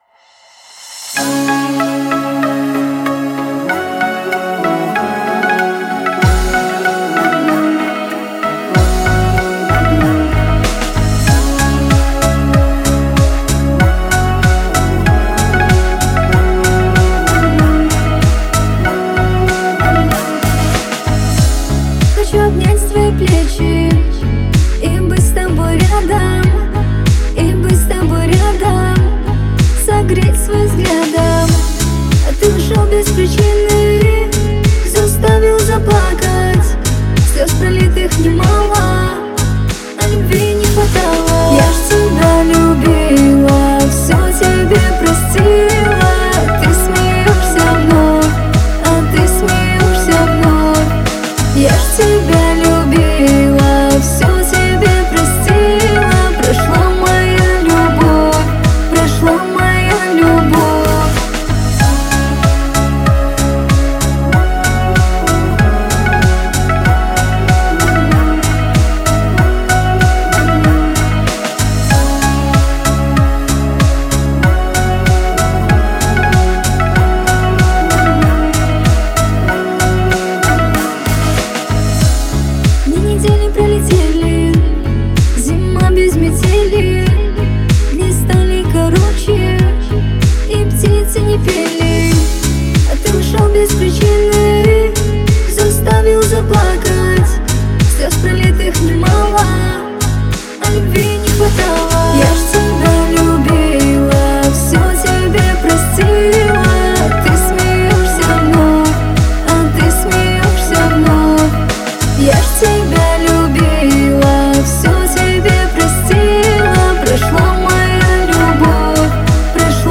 это трек в жанре поп с элементами баллады